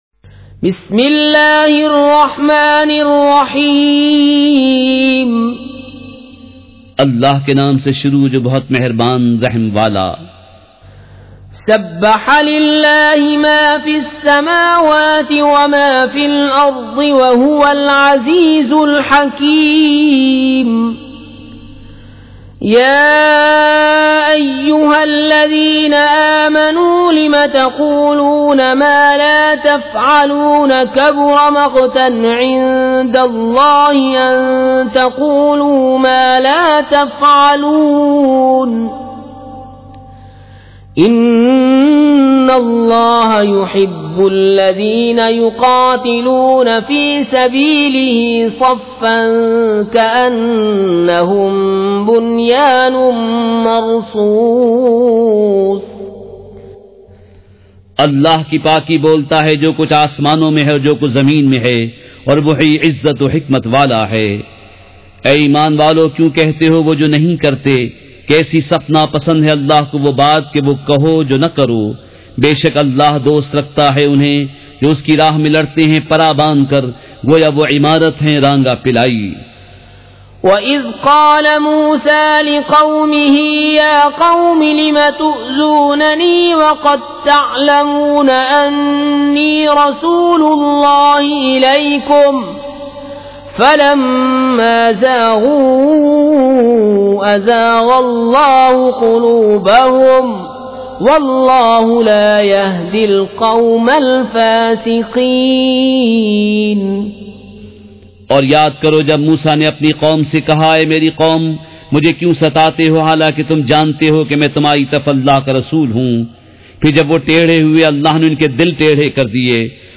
سورۃ الصّف مع ترجمہ کنزالایمان ZiaeTaiba Audio میڈیا کی معلومات نام سورۃ الصّف مع ترجمہ کنزالایمان موضوع تلاوت آواز دیگر زبان عربی کل نتائج 1723 قسم آڈیو ڈاؤن لوڈ MP 3 ڈاؤن لوڈ MP 4 متعلقہ تجویزوآراء
surah-as-saff-with-urdu-translation.mp3